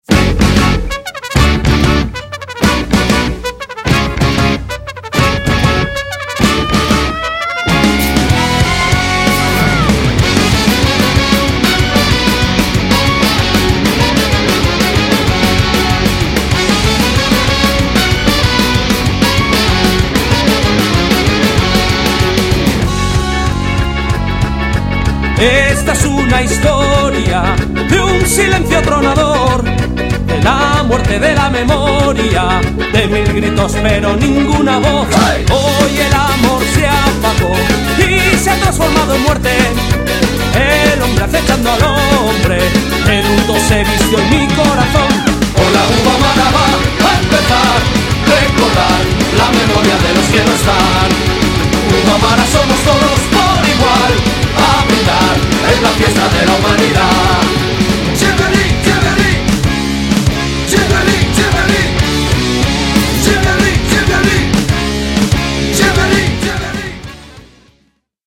そのサウンドはストレートで全開、CDからも充分に伝わるパワーに熱くなることは必至！
そのエキゾチックなメロディが東欧ジプシー音楽風味を色漬け、新鮮さをプラスしている。